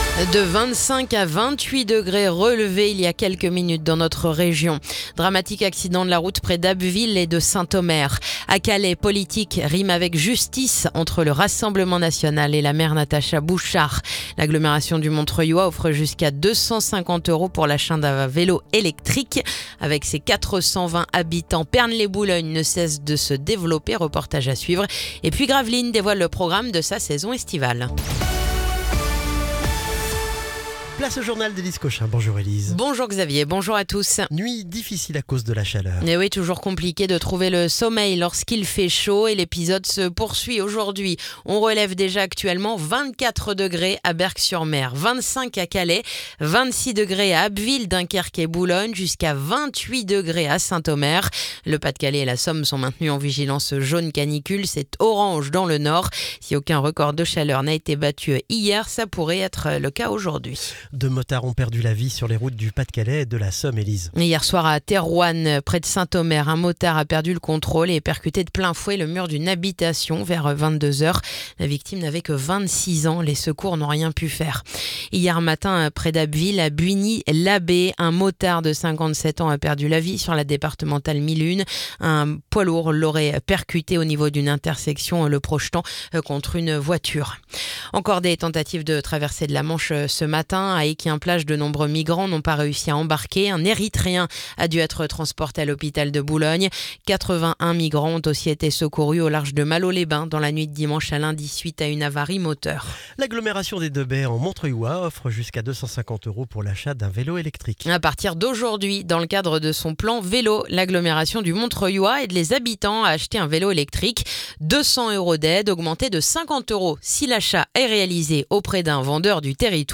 Le journal du mardi 1er juillet